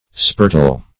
Spurtle \Spur"tle\ (sp[^u]r"t'l), v. t. [Freq. of spurt.]